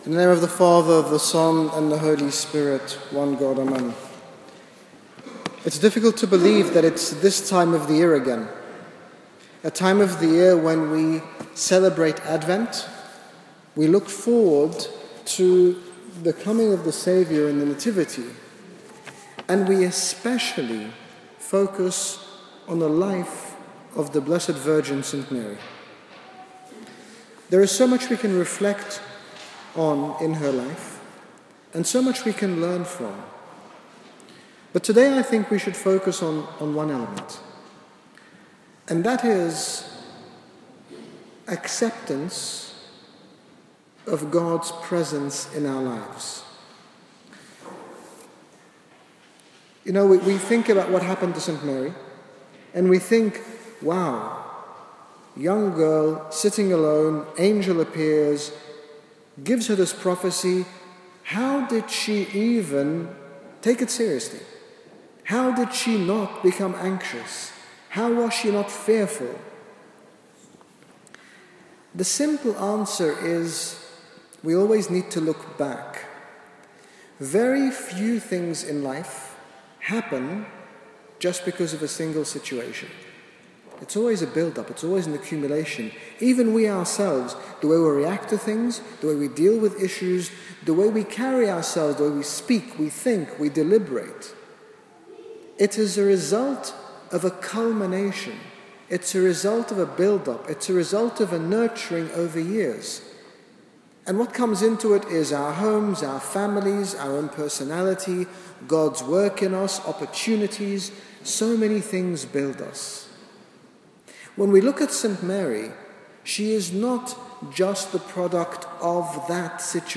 In this short sermon, His Grace Bishop Angaelos, General Bishop of the Coptic Orthodox Church in the United Kingdom, speaks about the faith of Saint Mary and her acceptance of God's will in her life. His Grace speaks about how we are a product of our environments and experiences, and reassures us that Saint Mary became the person she was through living a righteous life on a daily basis, which is both attainable and accessible to us today.